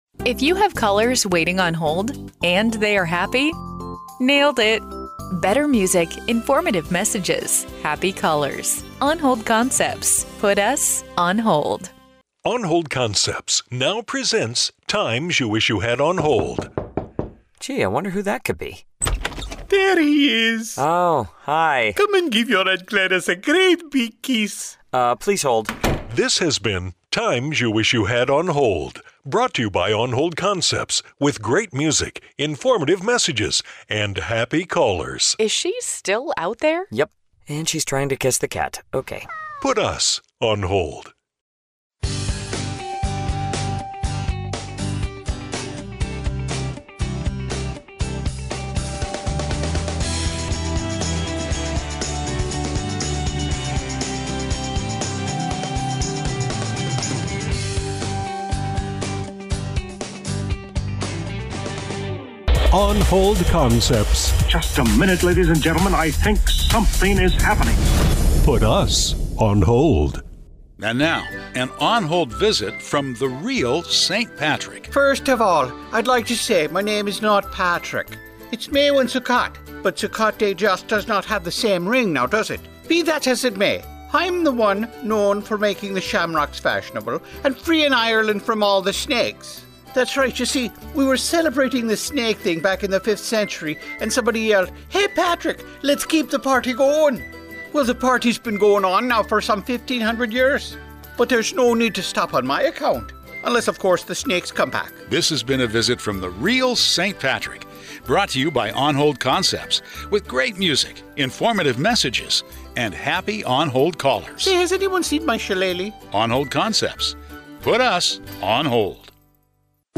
Put US on hold! 0:29 A Time You Wish You Had On-Hold 0:49 The Real Saint Pat 0:36 On Hold Wish 0:39 Cheer Team 1:00 Pot O'Gold 0:29 DBI Easter Bunny 6:37 Fully-Produced On Hold Program